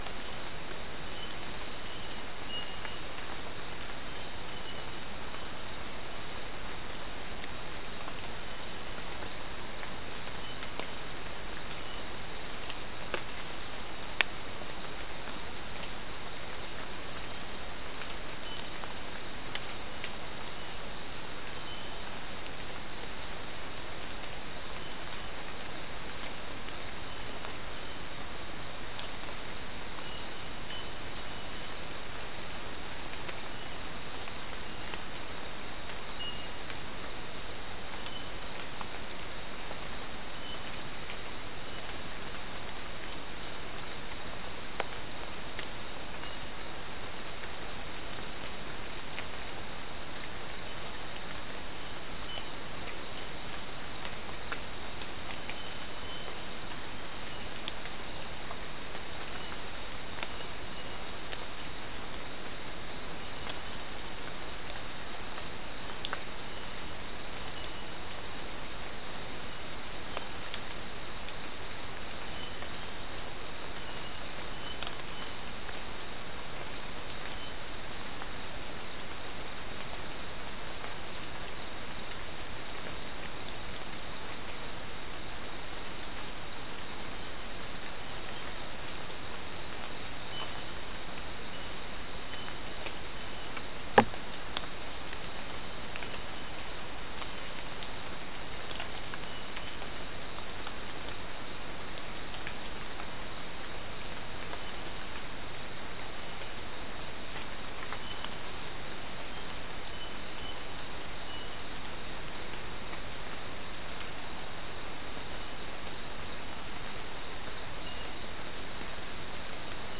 Soundscape
South America: Guyana: Turtle Mountain: 2
Recorder: SM3